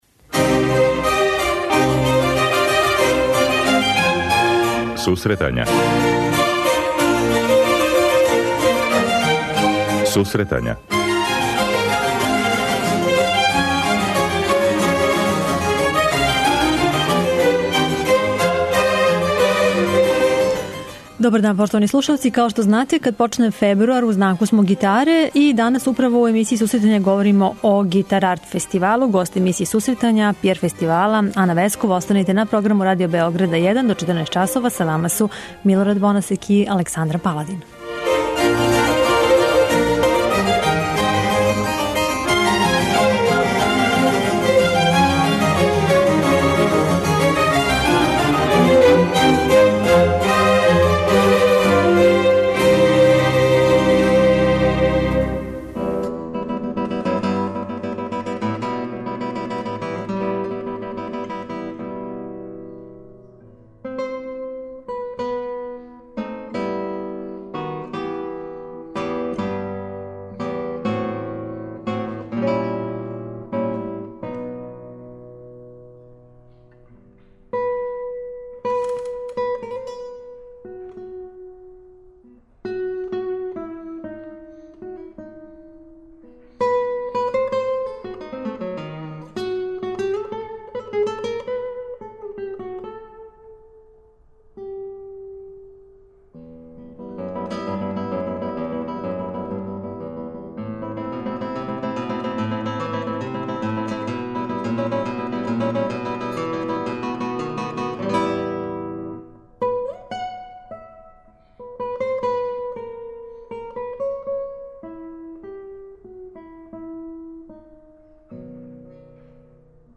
преузми : 23.31 MB Сусретања Autor: Музичка редакција Емисија за оне који воле уметничку музику.